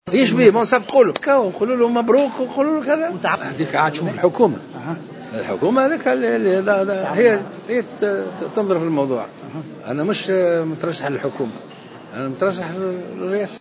قال المترشح للإنتخابات الرئاسية الباجي قايد السبسي خلال ندوة صحفية لعرض برنامجه الإنتخابي اليوم الإثنين 15 ديسمبر 2014 أنه في حال فوز المرزوقي بمنصب رئيس الجمهورية فإنه سيصفق له ويهنئه بفوزه.